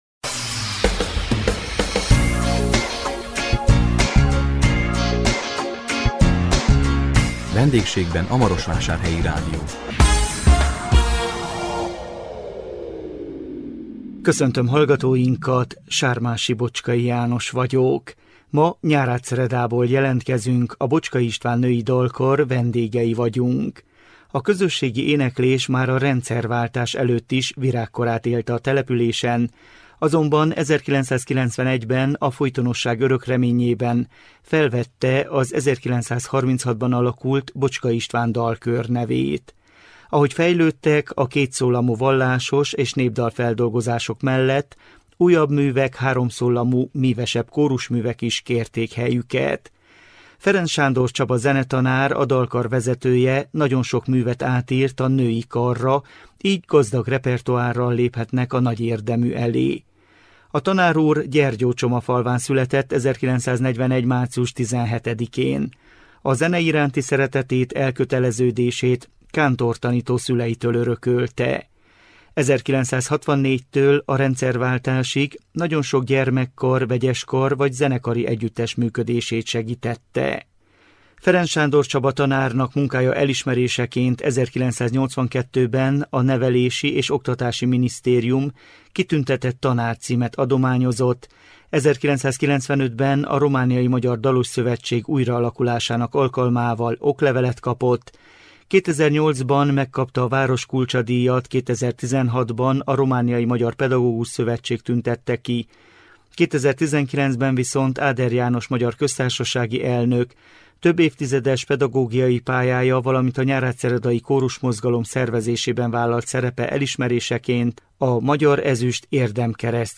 A 2026 április 16-án közvetített VENDÉGSÉGBEN A MAROSVÁSÁRHELYI RÁDIÓ című műsorunkkal Nyárádszeredából jelentkeztünk, a Bocskai István Női Dalkar vendégei voltunk.
Ahogy fejlődtek a kétszólamú vallásos- és népdalfeldolgozások mellett újabb művek, háromszólamú, mívesebb kórusművek is kérték helyüket.